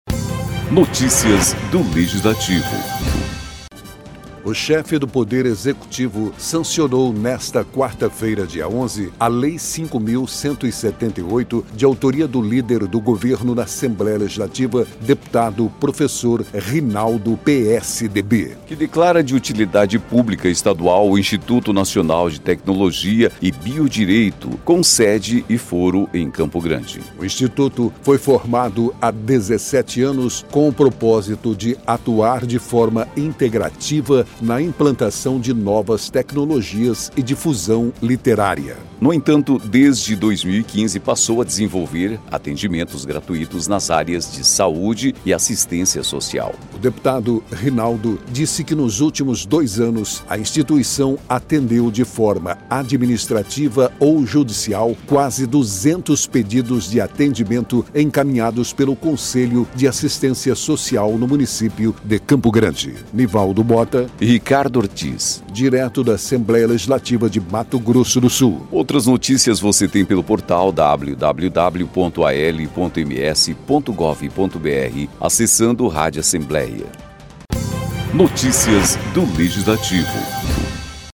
Locução